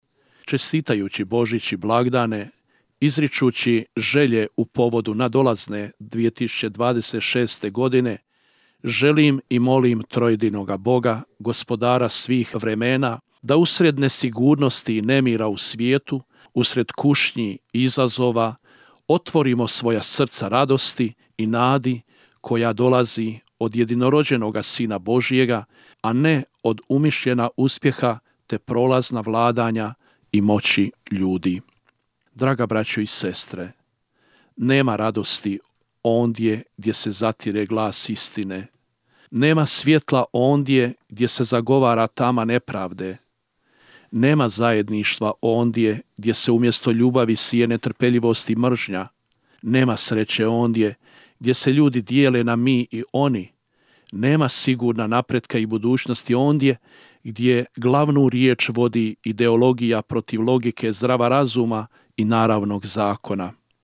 U svečanu ozračju došašća i priprave za svetkovinu Božića u ovoj Jubilejskoj godini posebnom snagom odjekuju riječi: „Javljam vam blagovijest, veliku radost za sav narod! Danas vam se u gradu Davidovu rodio Spasitelj – Krist, Gospodin!“, poručio je u božićnoj čestitci porečko-pulski biskup monsinjor Ivan Štironja: (